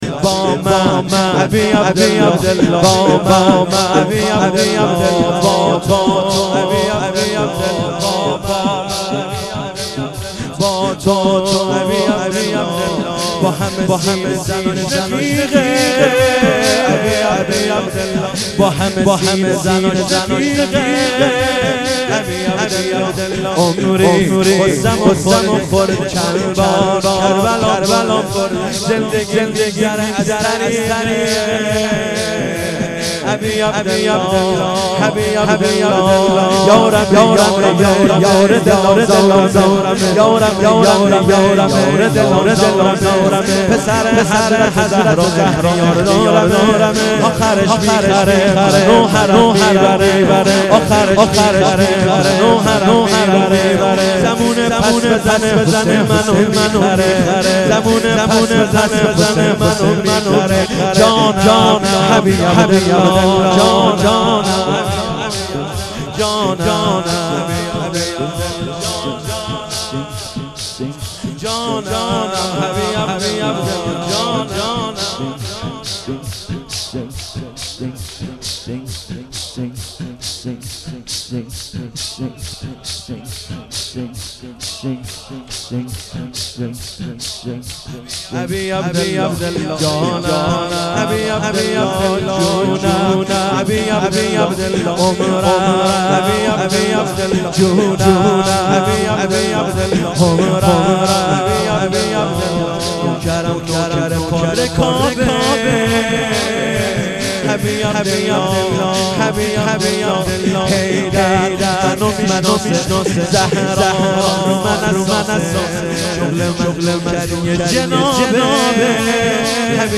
مداحی های محرم